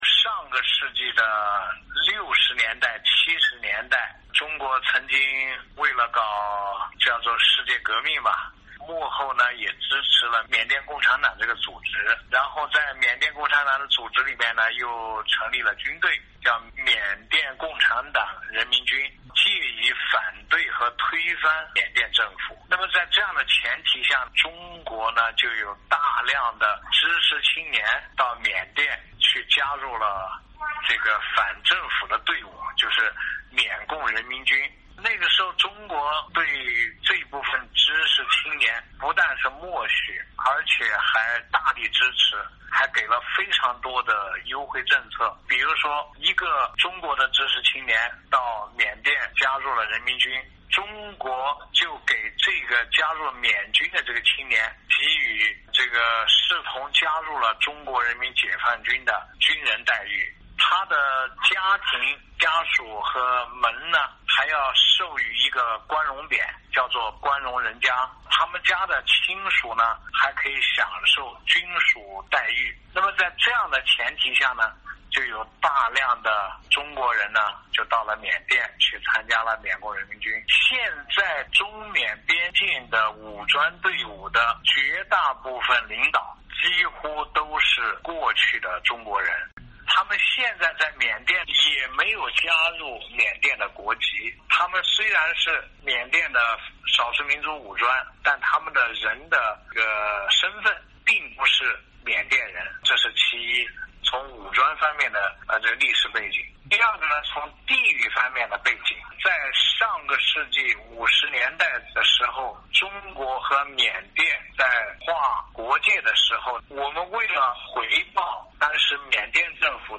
（未完待续，据电话录音整理）